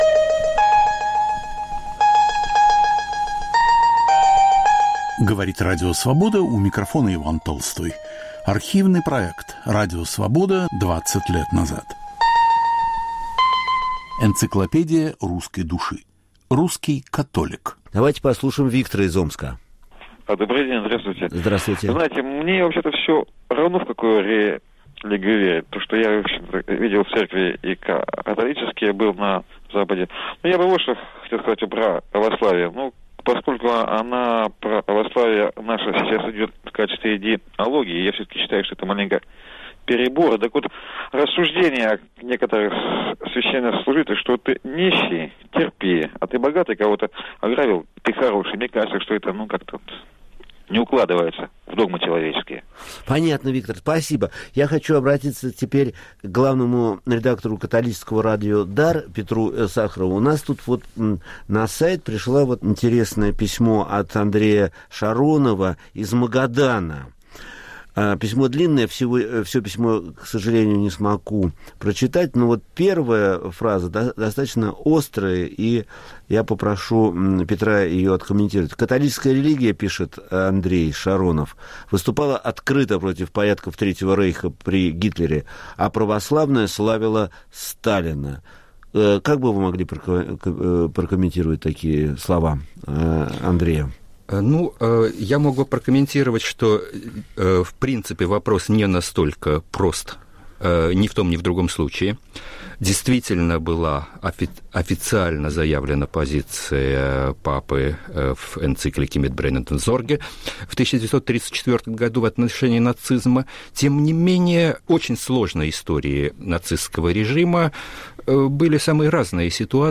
Автор и ведущий Виктор Ерофеев.